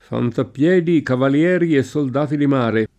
fantappL$di, kavalL$ri e SSold#ti di m#re] (Davanzati) — sim. il cogn.